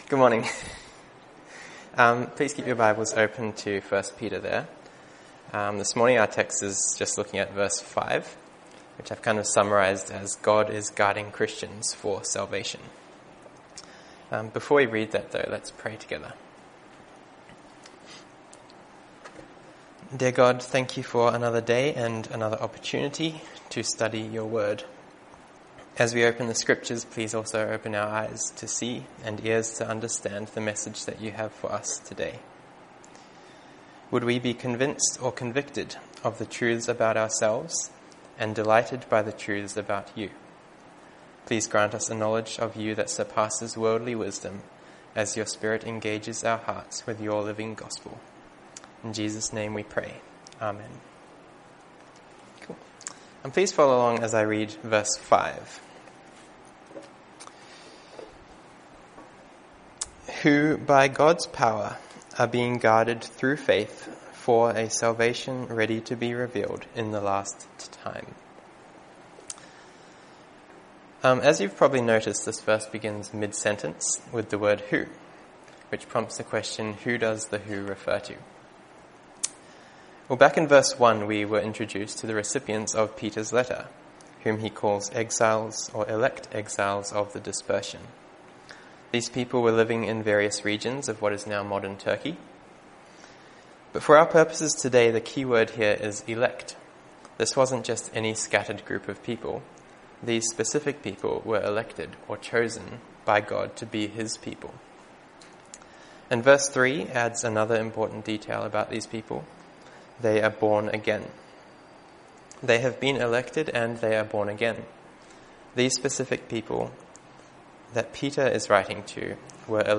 Download mp3 Previous Sermon of This Series Next Sermon of This Series Coming Soon